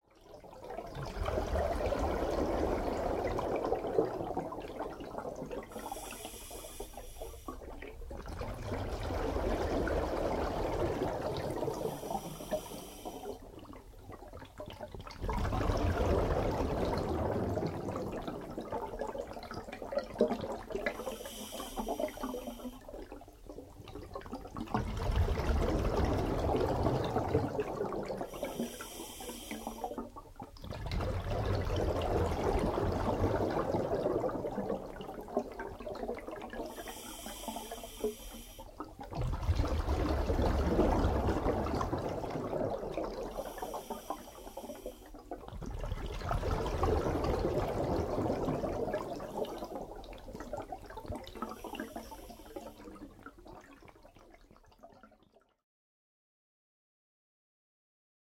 Звуки дайвинга
Здесь вы найдете записи шумов акваланга, пузырей воздуха, скрипа снаряжения и других уникальных звуков морских глубин.